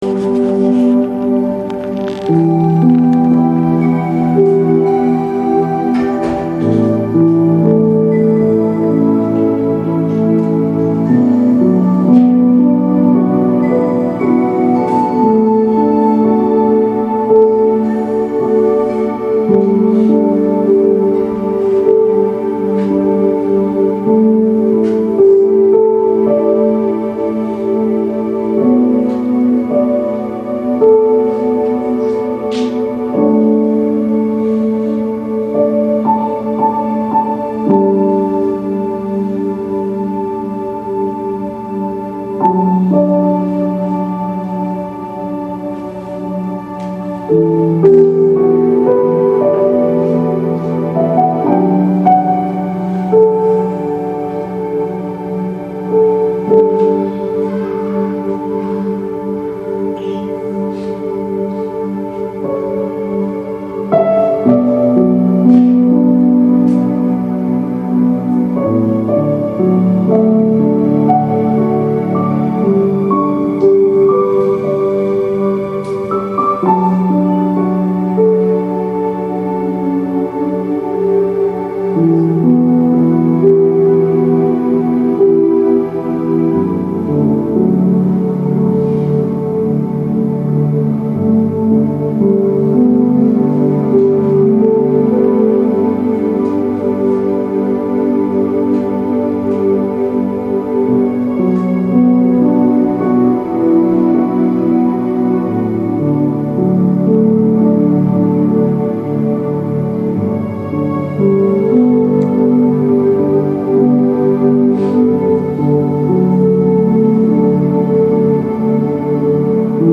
主日恩膏聚会